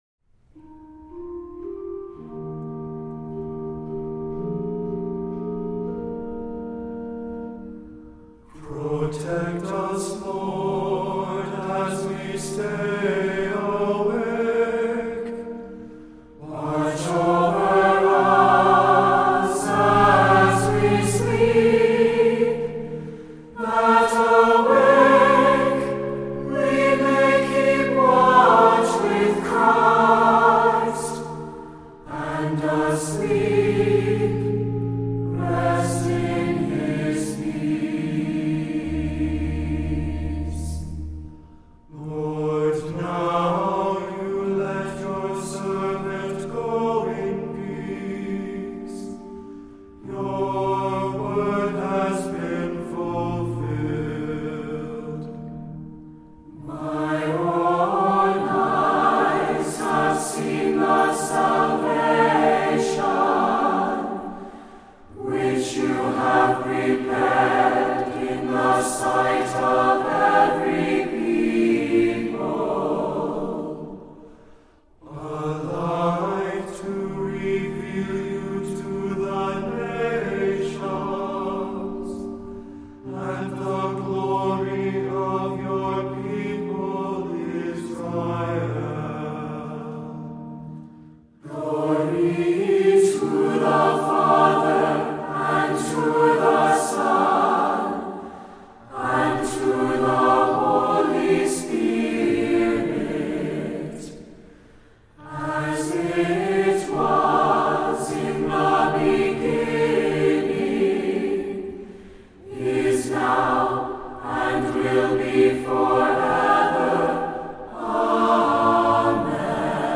Voicing: Unison choir; Cantor; Assembly